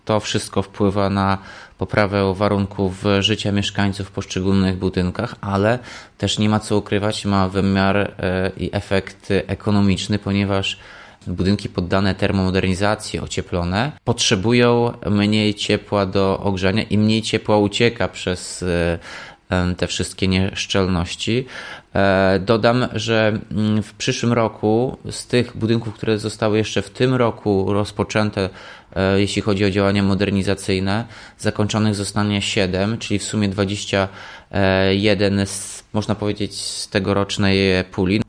W mijającym roku zrealizowanych zostało 14 takich inwestycji, mówi rezydent Ełku Tomasz Andrukiewicz.